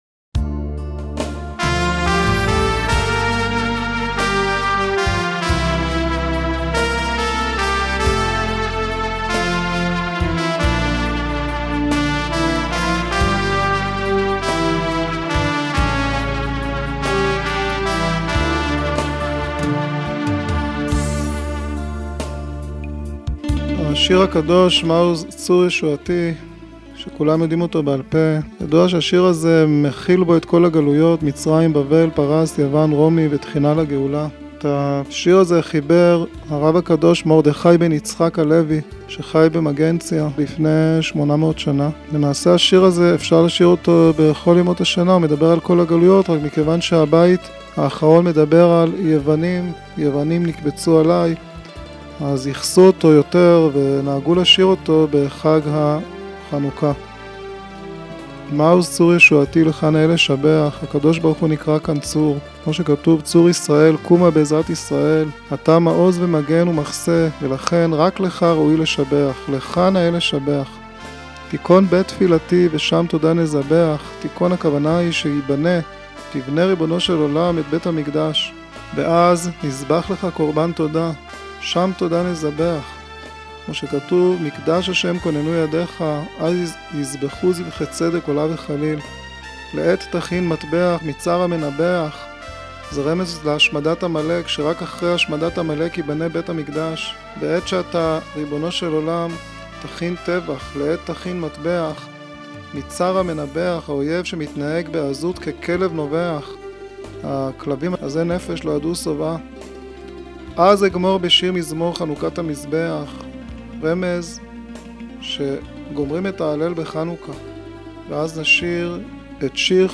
שיעור מיוחד לגולשי האתר